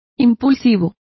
Complete with pronunciation of the translation of impulsive.